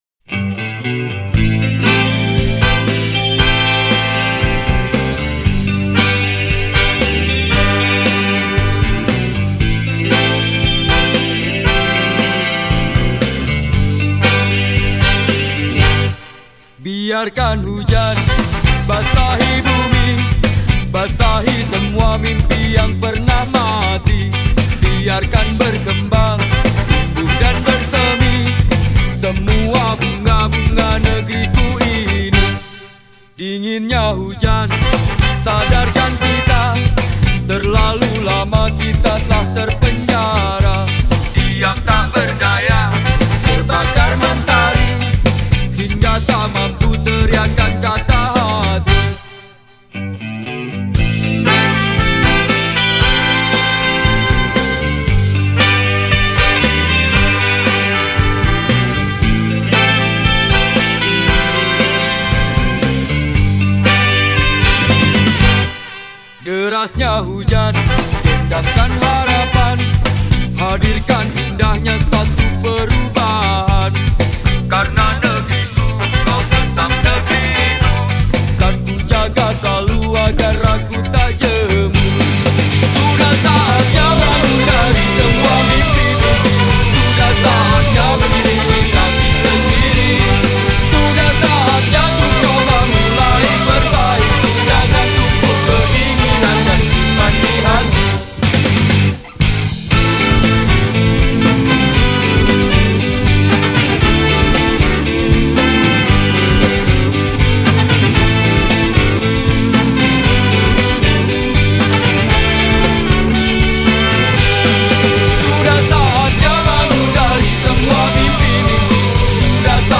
Genre Musik                              : SKA Rock, Pop